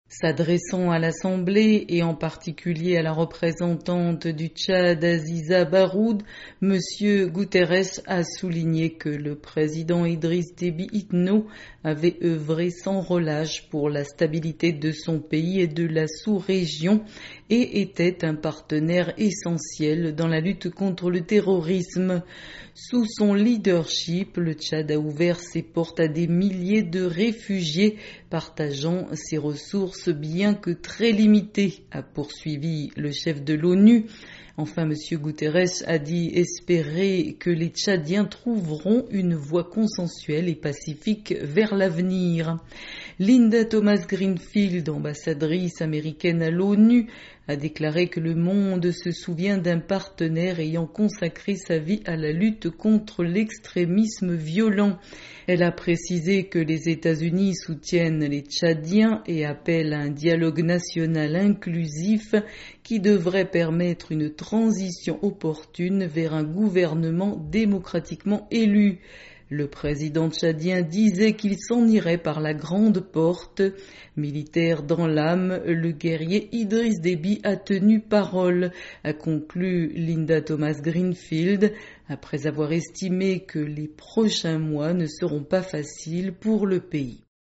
Hommage au président tchadien Idriss Déby Itno à l'ONU
Antonio Guterres et plusieurs Représentants, dont l'ambassadrice américaine Linda Thomas-Greenfield, ont rendu hommage au président tchadien Idriss Déby Itno, mort dans des combats contre les rebelles le 20 avril.